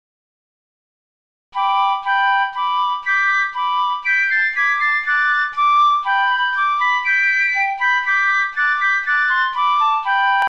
Basler Märsch
(numme d Aafäng)